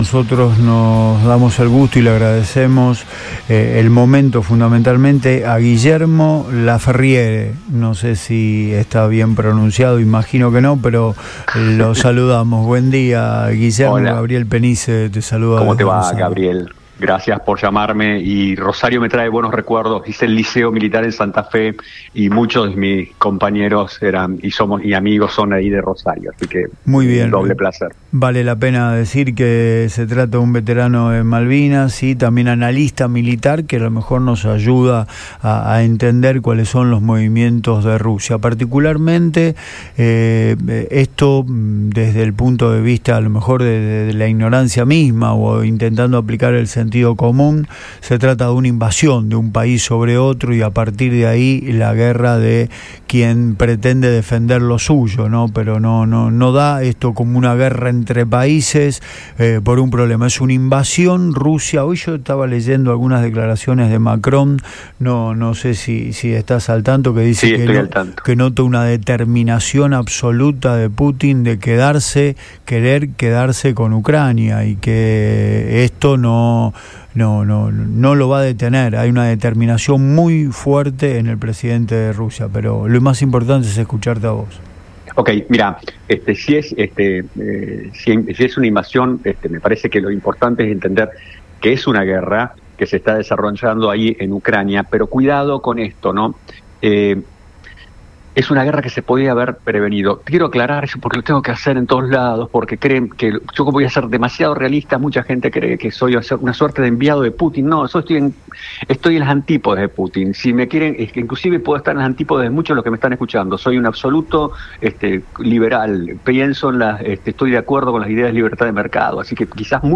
en diálogo con Antes de Todo por Radio Boing